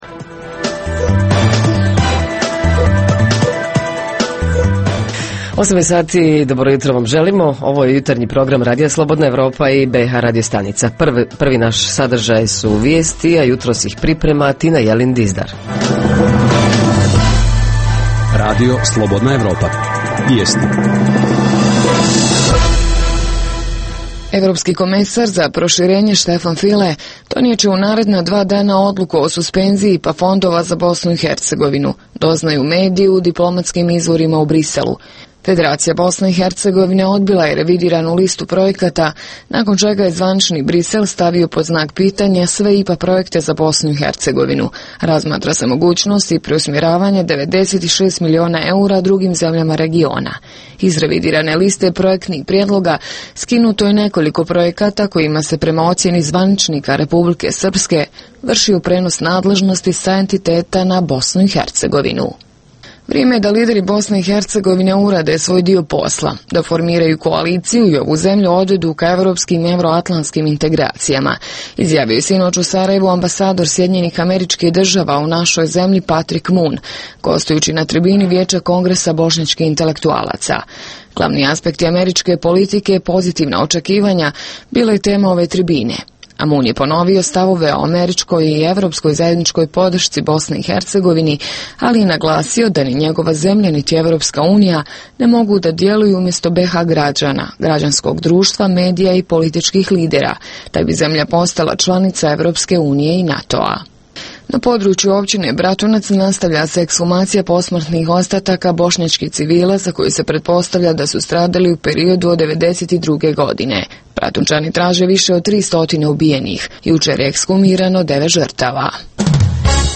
Redovni sadržaji jutarnjeg programa za BiH su i vijesti i muzika.